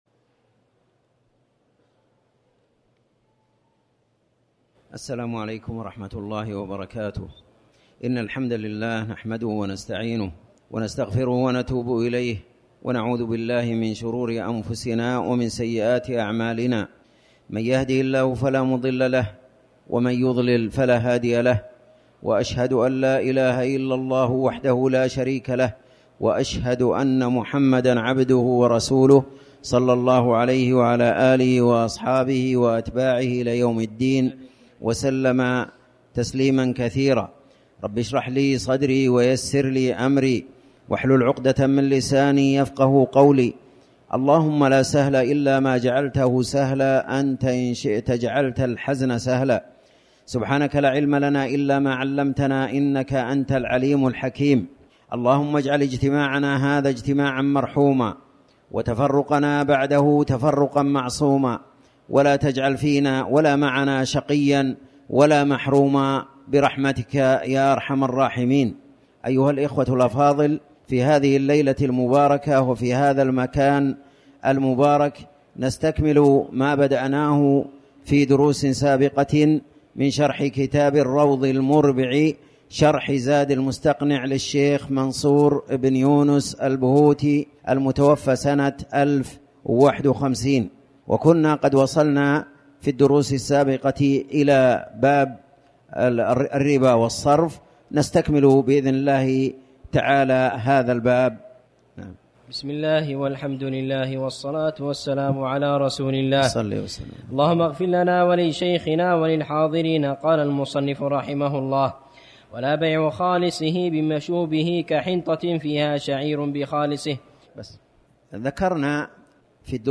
تاريخ النشر ١٦ جمادى الأولى ١٤٤٠ هـ المكان: المسجد الحرام الشيخ